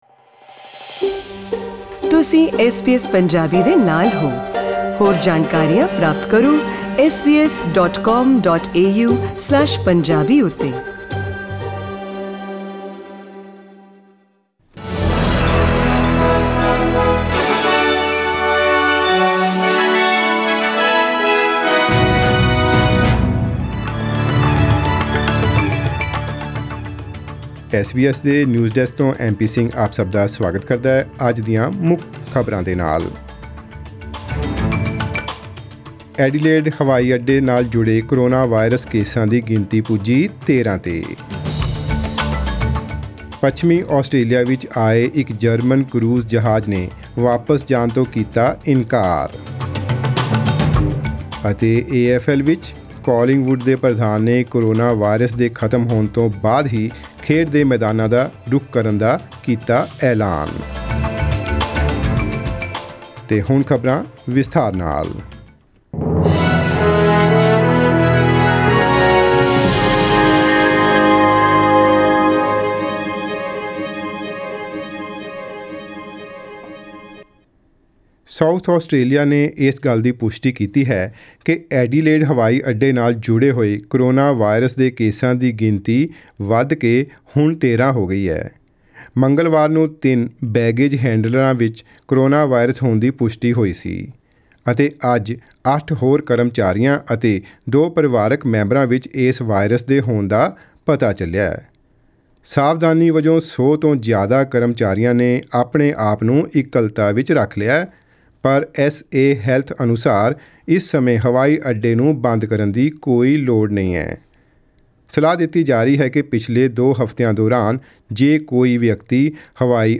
Australian News in Punjabi: 1 April 2020